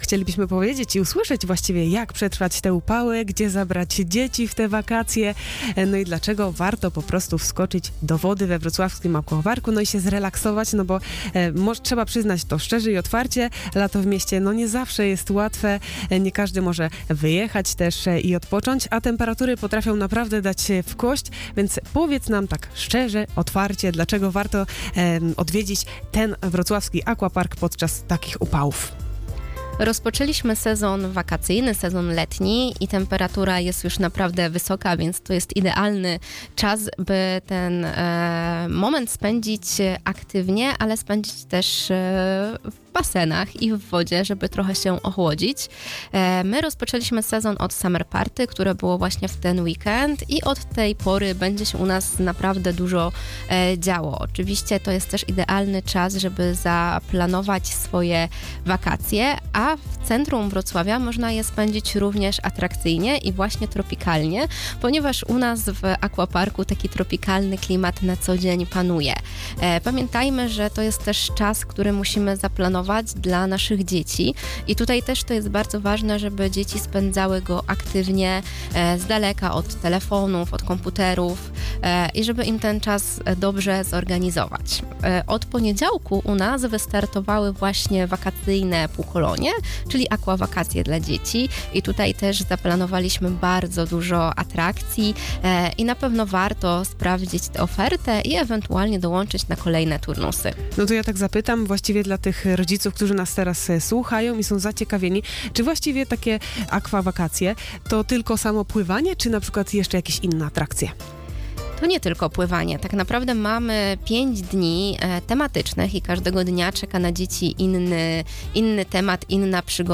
rozmowa_lato-w-aquaparku.mp3